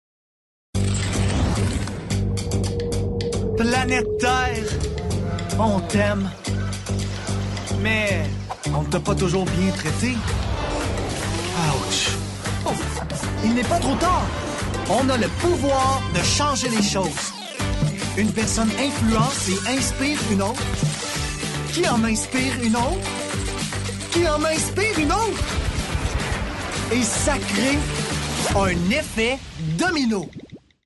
Narration 1 - FR